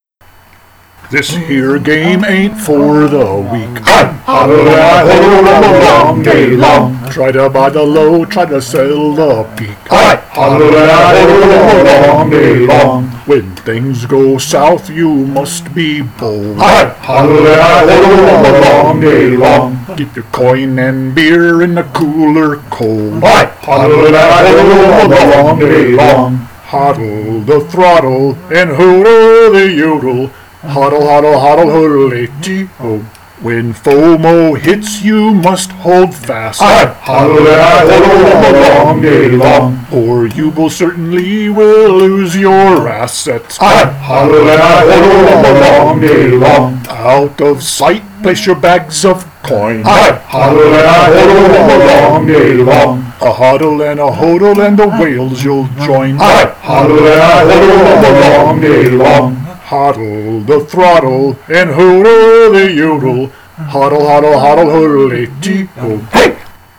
Just imagine that you're in a pub with your best crypto buddies, and go with it.
AKA, The Hodl Hodl Yodel Song...a crypto shanty.